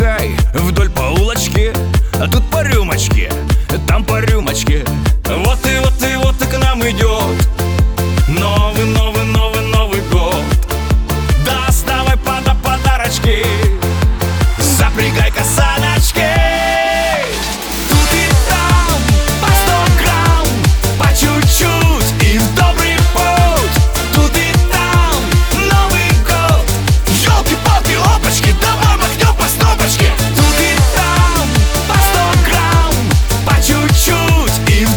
Жанр: Русская поп-музыка / Русский рок / Русские
# Chanson in Russian